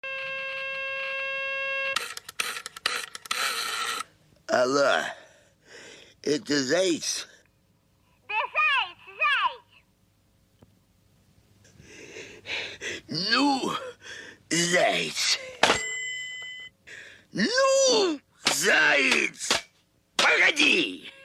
10. Варианты произношения «Ну, Погоди!»